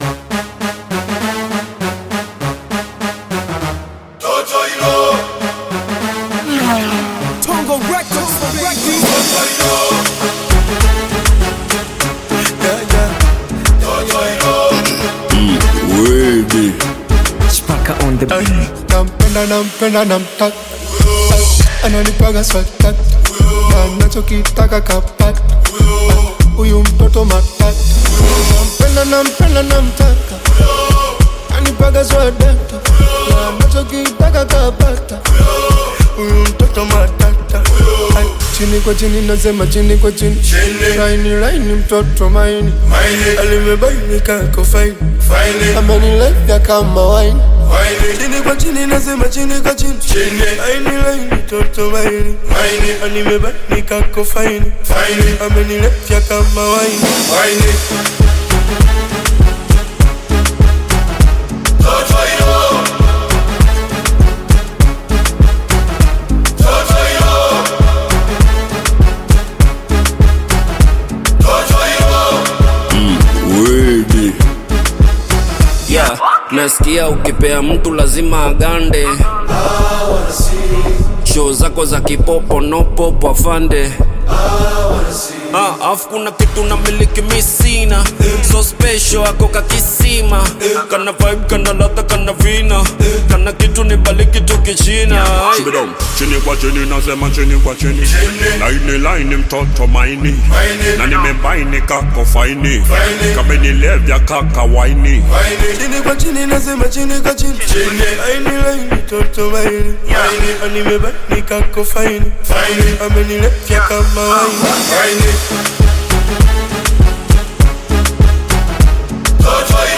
Bongo Flava
Bongo Flava song